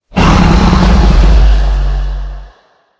growl2.ogg